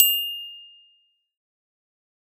Звуки зубов
Звук сверкающих зубов блеснуло